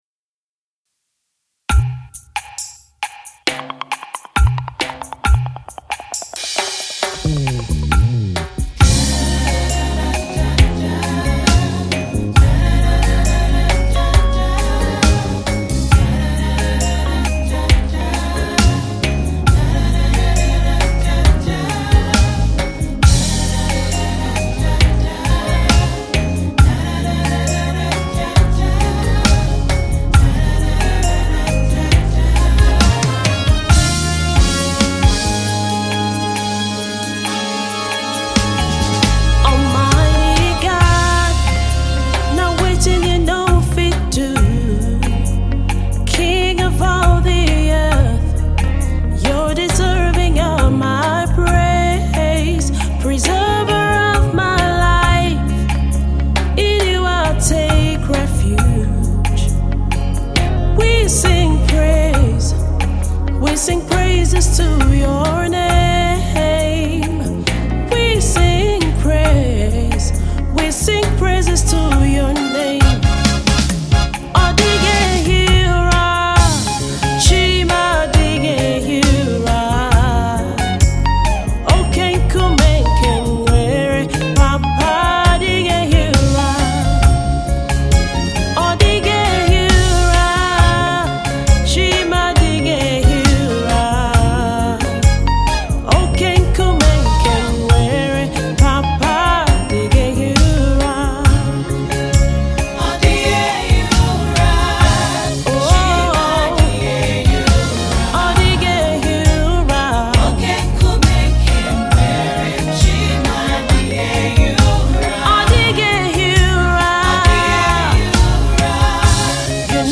a fusion of traditional and contemporary soul.